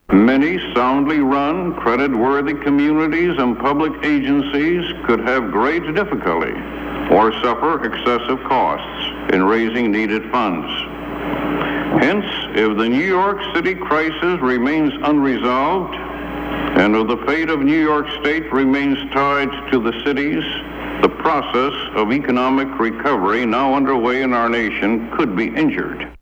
Arthur Burns tells a joint Congressional committee that the federal government cannot afford to bail New York City out of its economic crisis
Broadcast on CBS-TV, October 8, 1975.